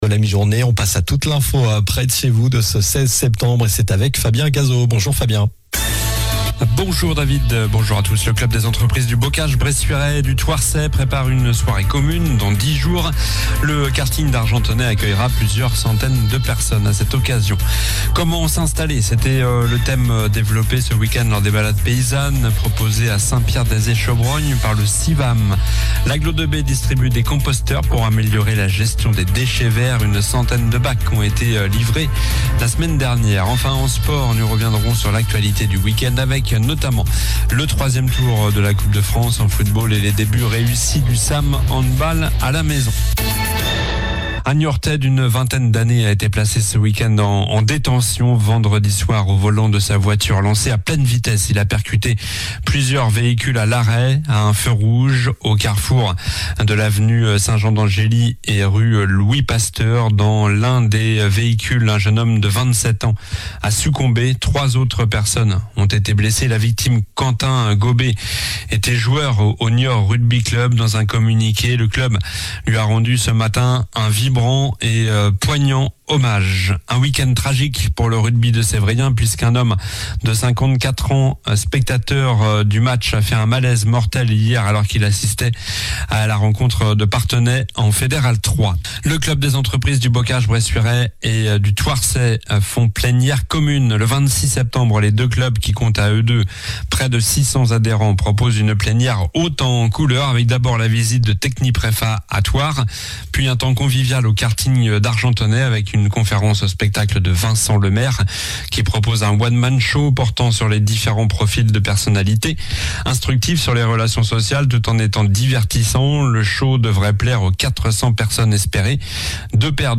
Journal du lundi 16 septembre (midi)